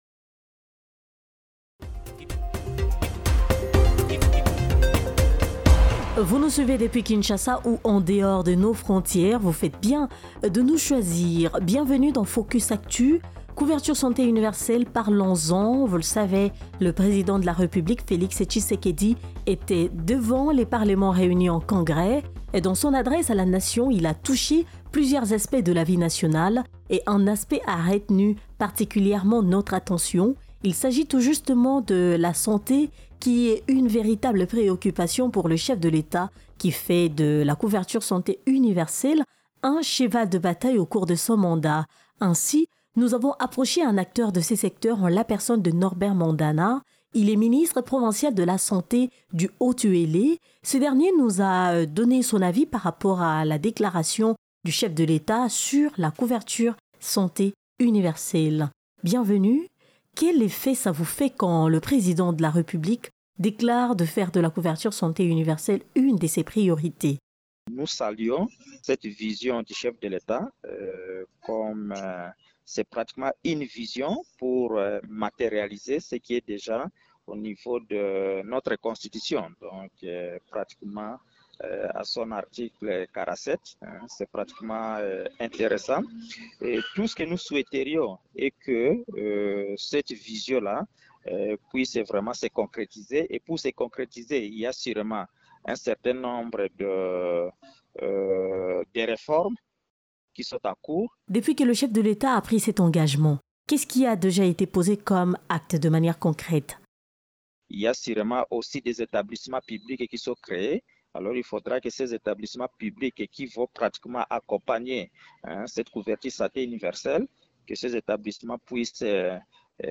Ainsi nous avons approché un acteur de ce secteur en la personne de Norbert MANDANA BAMBENONGAMA, Ministre provincial de la santé du Haut-Uélé afin qu’il donne son avis par rapport à la déclaration de Félix TSHISEKEDI sur la CSU.